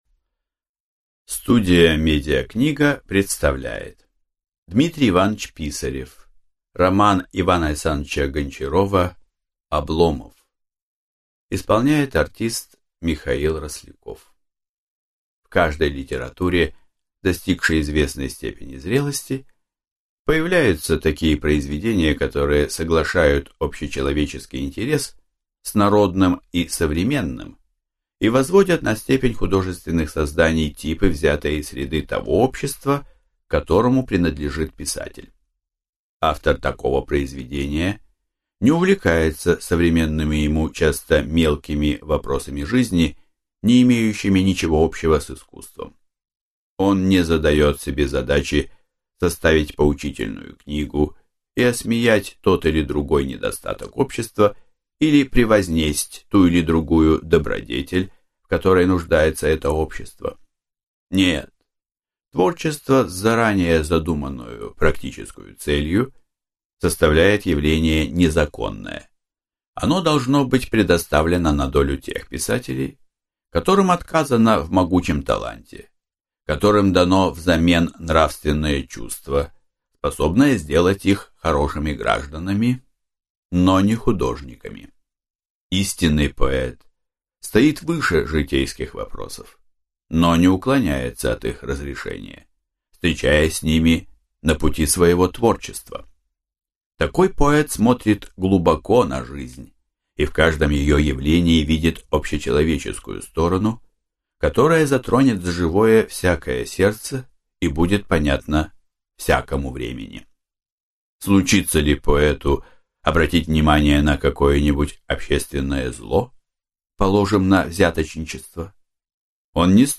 Аудиокнига Роман И. А. Гончарова «Обломов» | Библиотека аудиокниг